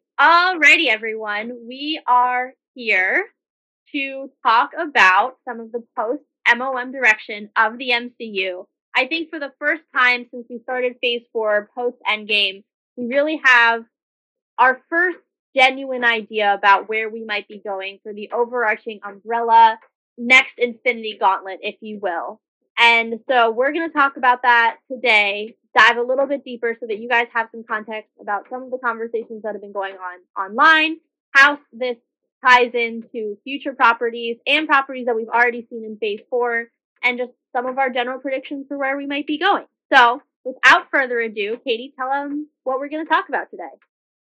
Sharp Recurring Background Noise
Not only was the dripping very loud, but extremely broadband. Lastly, some of the dialog was clipped, whilst at other times, much softer, almost like the person speaking was not keeping a constant distance from the mic.
The noise can be reduced but at a cost, reduction in the vocal quality, although not as bad as running it through the standard noise reduction. I have attached a cleaned version, as can be heard, the dripping is greatly reduced but will be impossible to completely remove.
BTW, to make the file smaller, I have made it a mono file, no need for stereo for dialogue.